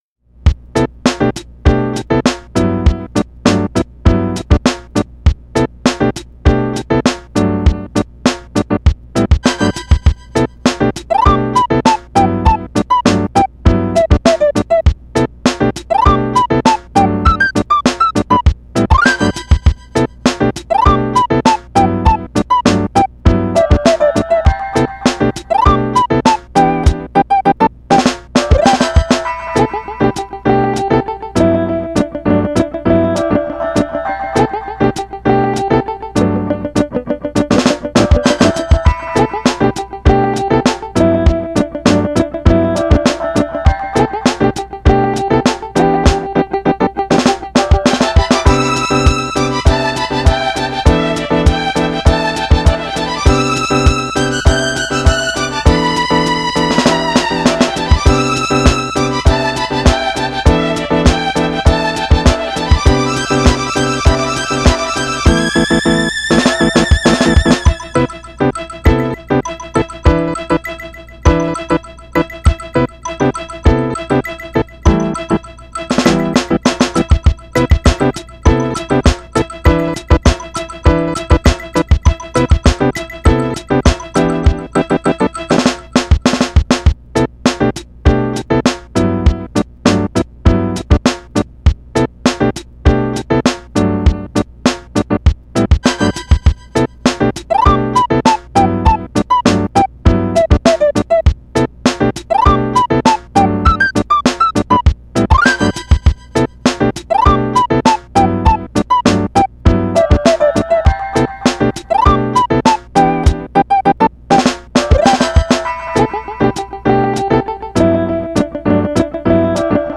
●Atmosphere:Super Nintendo (SNES) style urban BGM
The unique distortion of the sound is wonderful.
AttributesHappy Comical Peaceful Nostalgia
GenrePop EasyListening Electronic JapaneseStyle